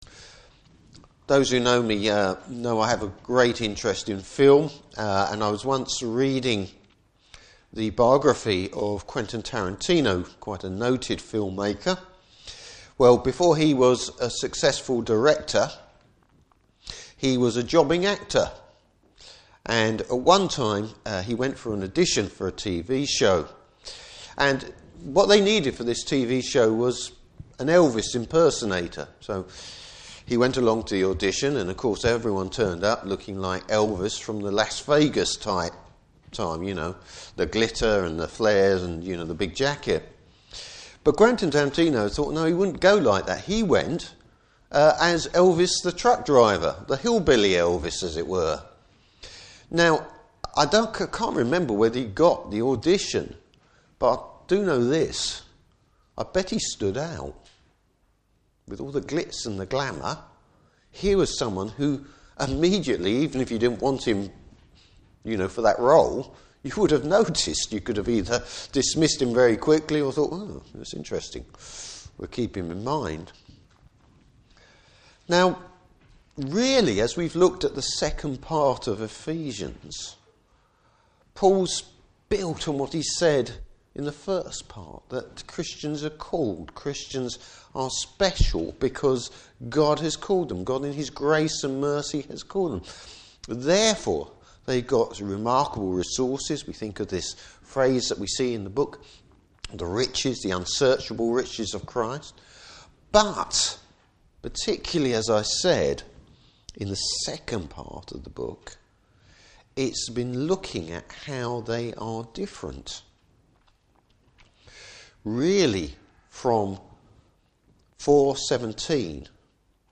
Service Type: Morning Service Bible Text: Ephesians 6:10-24.